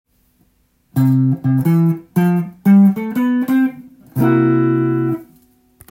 メジャー系フレーズ①
ドレミファで作られているので弾きやすいのも特徴です。
osyare.ending3.m4a